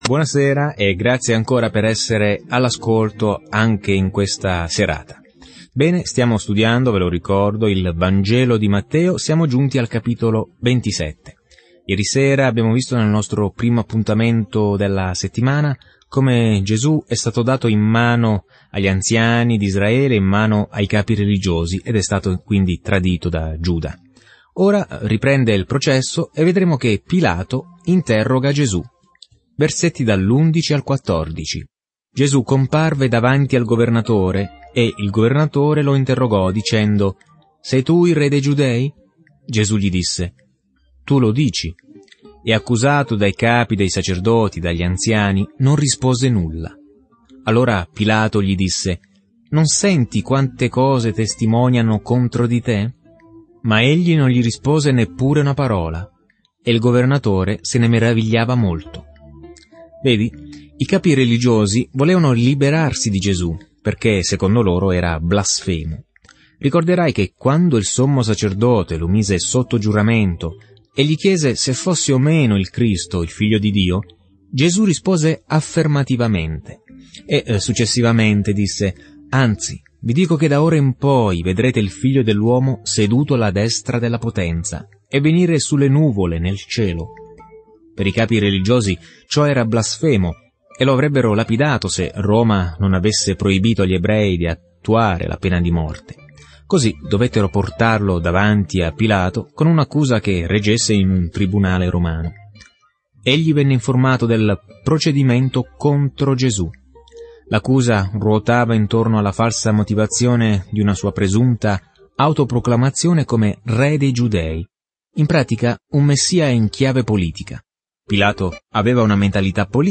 Viaggia ogni giorno attraverso Matteo mentre ascolti lo studio audio e leggi versetti selezionati della parola di Dio.